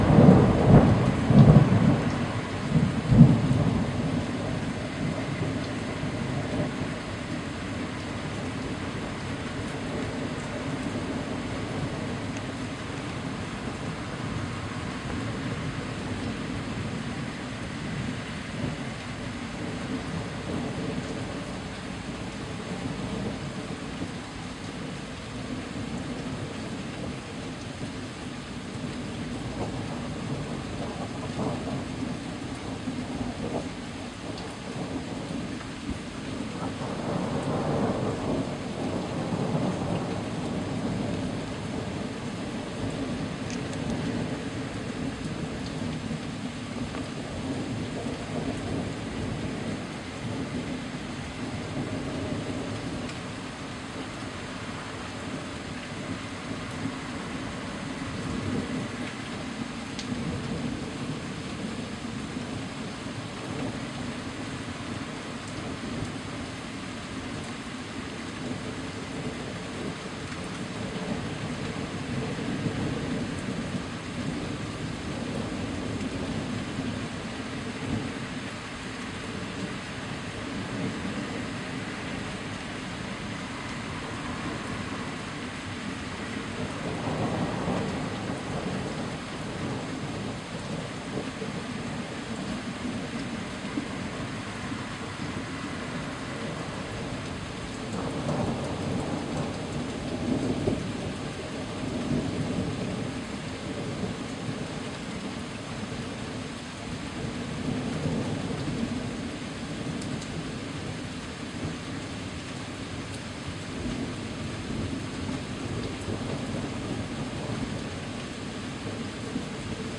自然的声音 " 风雨雷鸣 01 49秒
描述：记录在家，佛罗里达州暴雨。自然风天气风暴雷雨雷雨雷电雷雨
标签： 雷暴 暴雨 气候 雷电 暴雨 雷暴 自然
声道立体声